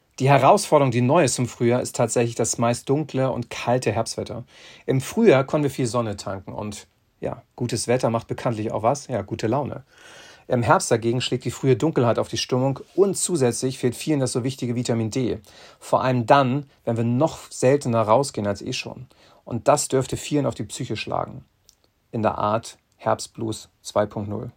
radioEXPERTEN - Ihr perfekter Interviewpartner